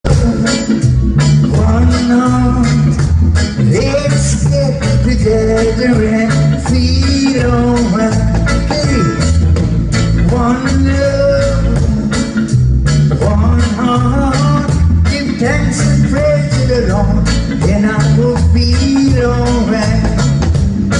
Außerdem verfügt das Restaurant über eine Bühne, auf der fast täglich live-Musik gespielt wird. Noch an diesem Abend spielte ein bekannter Reggae-Sänger, welcher unter anderem bereits auf dem Summer Jam in Köln gespielt hatte.
Reggae-Jamaika-Seven-Mile-Beach.mp3